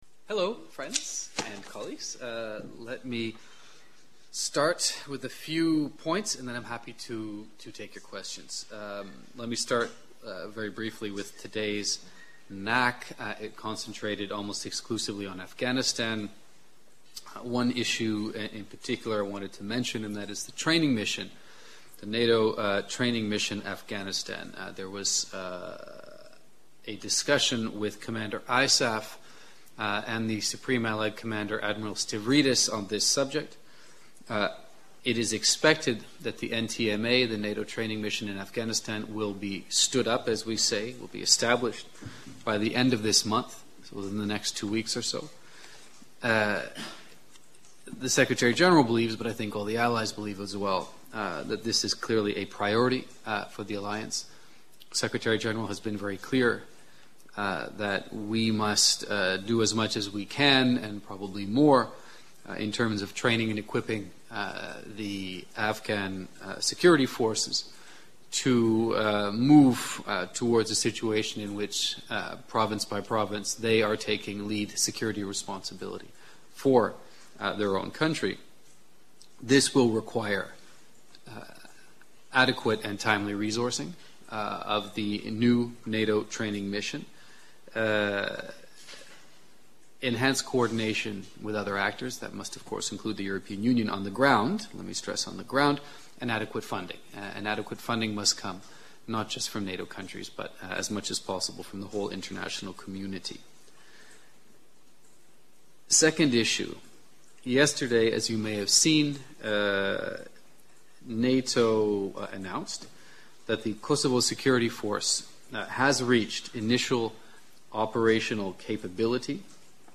Weekly press briefing